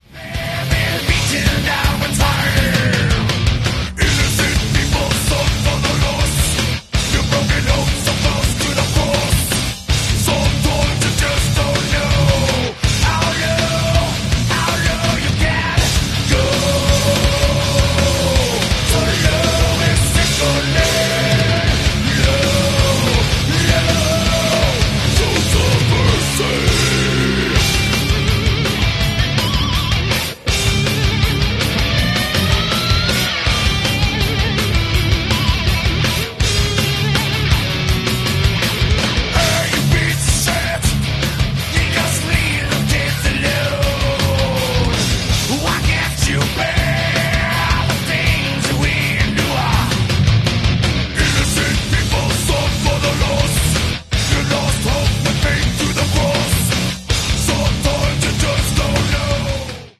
thrash metal